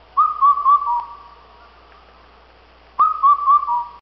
알림음 8_검은등뻐꾸기.ogg